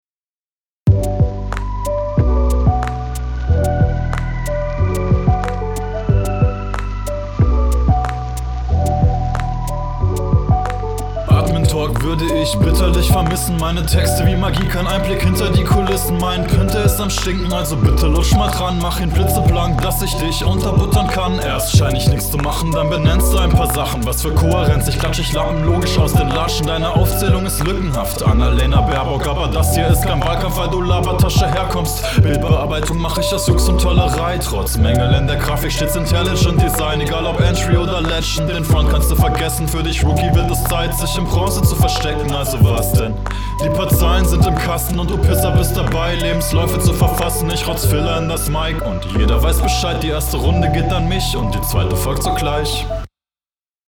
Das klingt besser gerappt als in der HR, aber ich glaube der Beat tut euch …